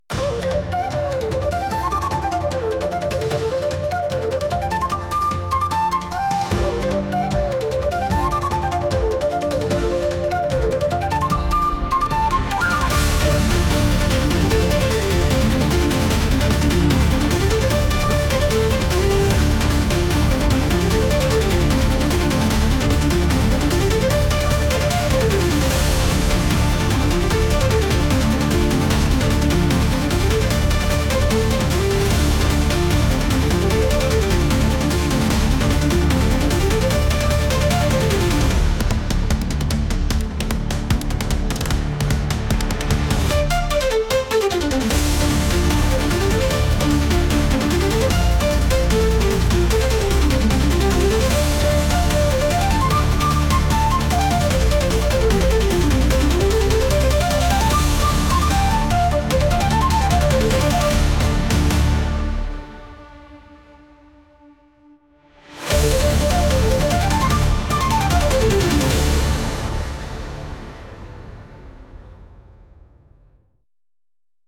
和楽器のバトルBGMな音楽です。